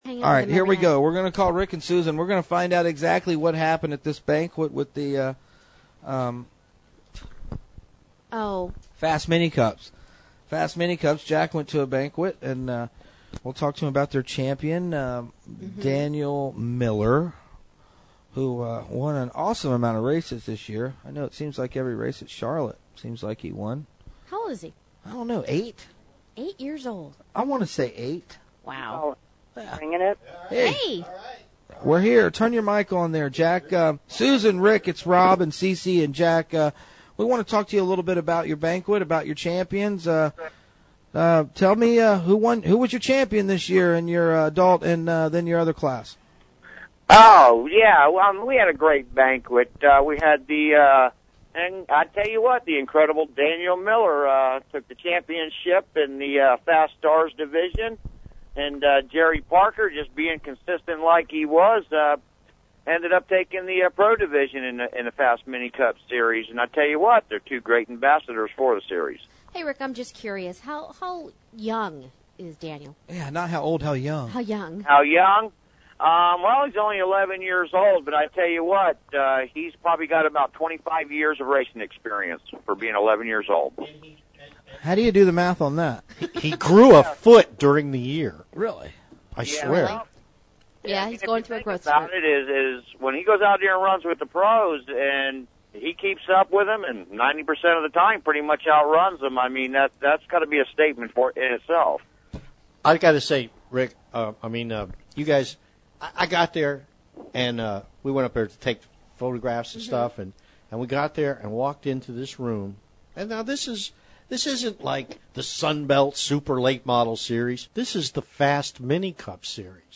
were guests on Monday (Jan14) on Real Racin USA's "Inside Florida Racing" show...